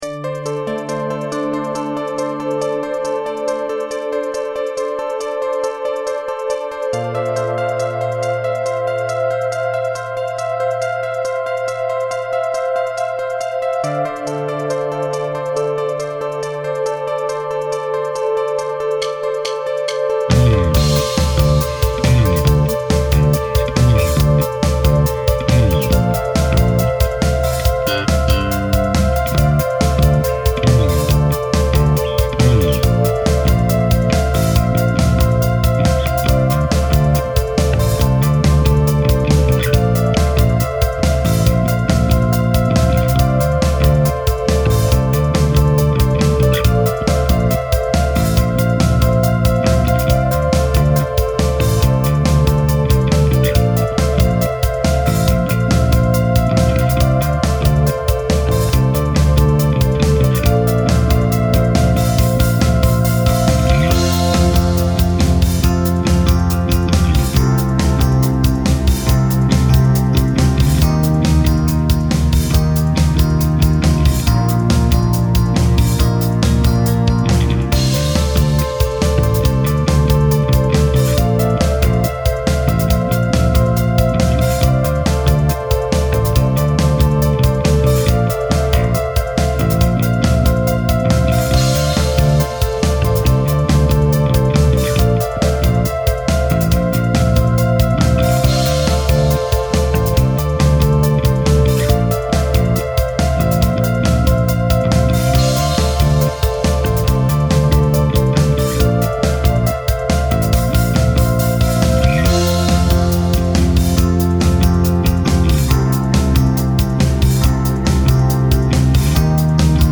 BPM : 139